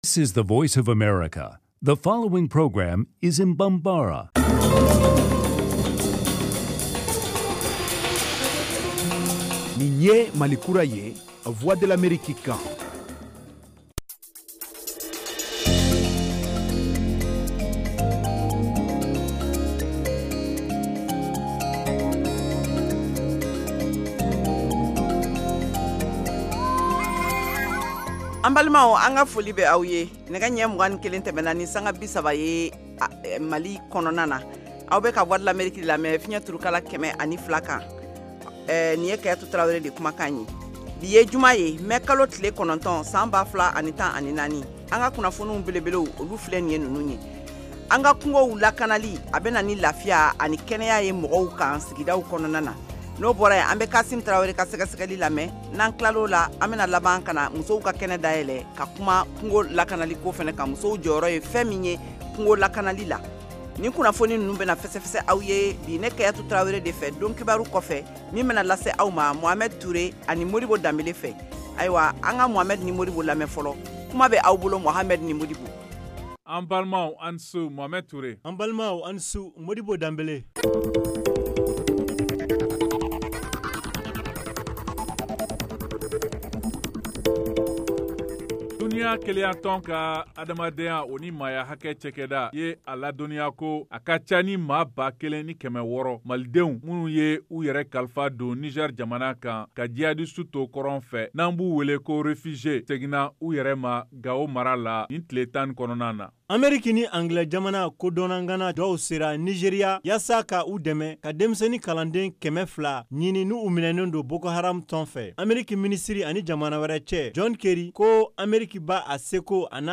Emission quotidienne en langue bambara
en direct de Washington. Au menu : les nouvelles du Mali, les analyses, le sport et de l’humour.